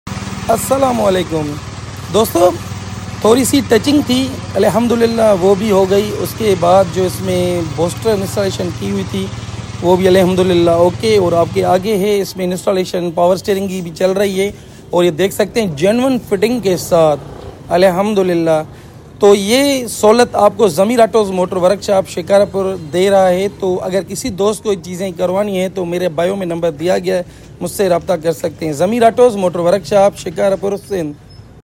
Suzuki Mehran Booster brake sound effects free download